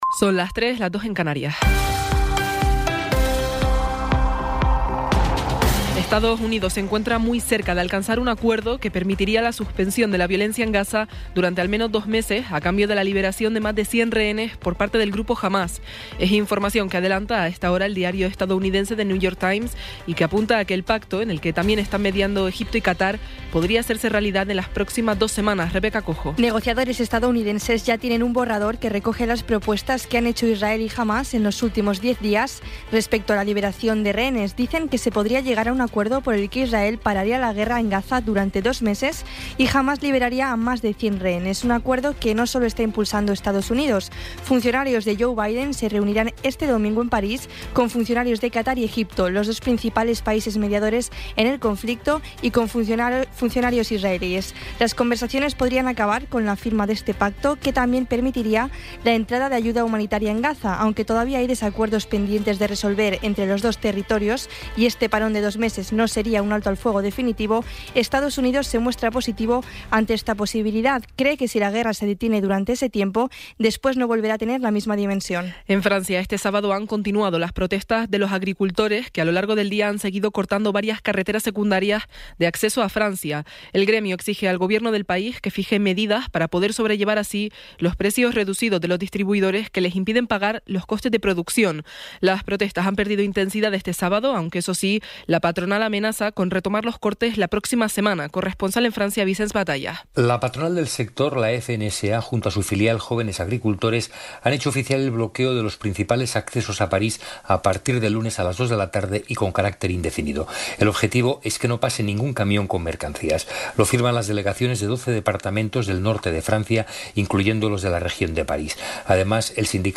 Resumen informativo con las noticias más destacadas del 28 de enero de 2024 a las tres de la mañana.